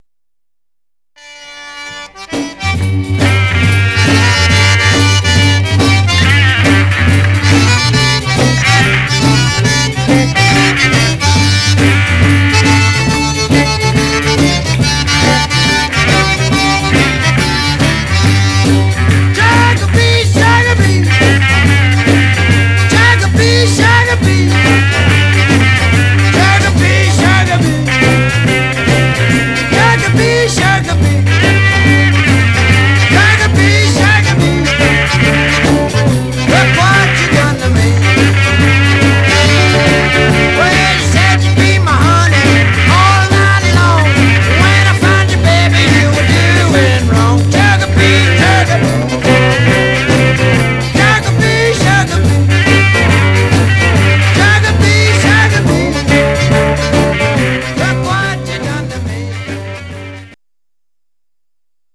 Stereo/mono Mono
Rockabilly Condition